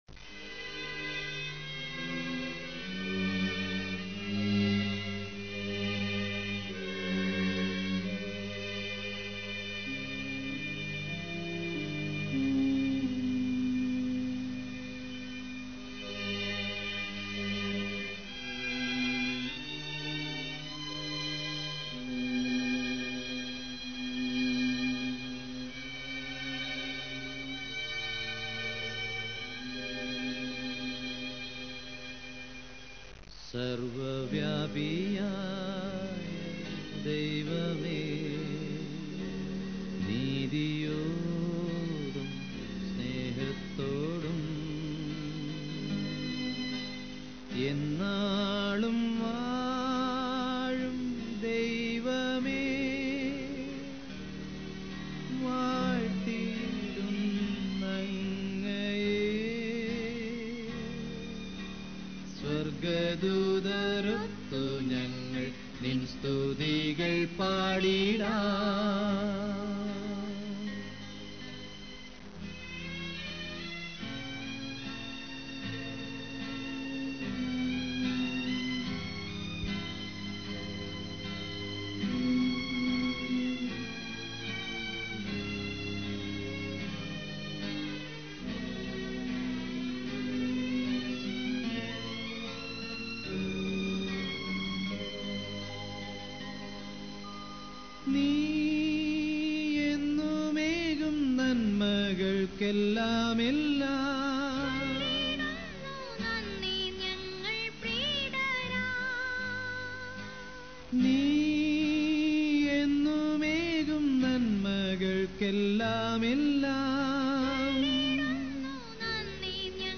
Malayalam Devotionals
46 christian